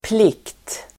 Uttal: [plik:t]